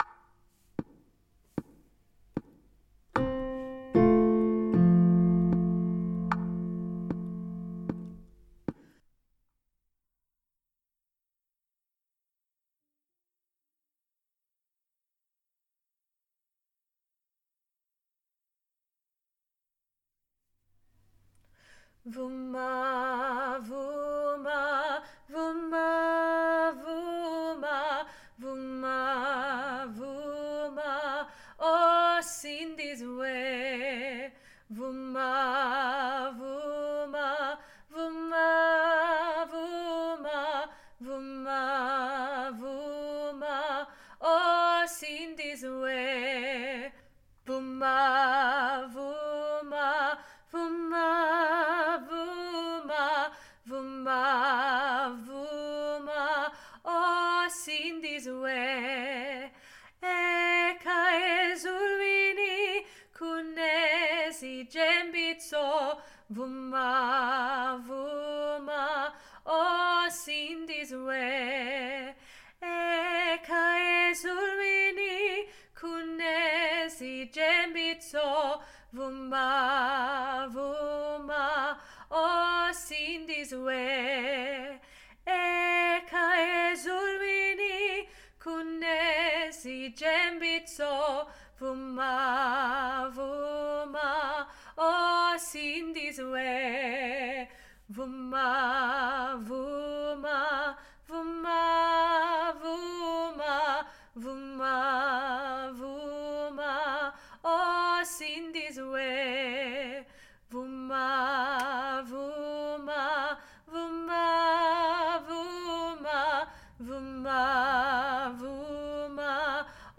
Alto Vuma